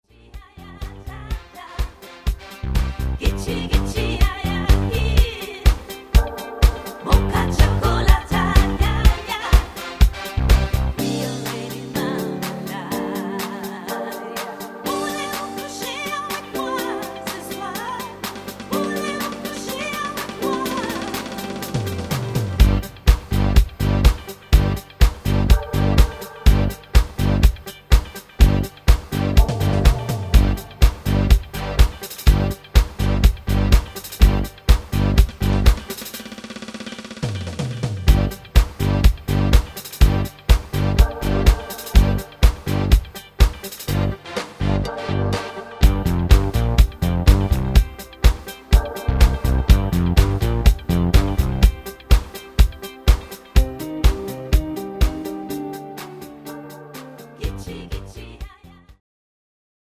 fetziger Disco Titel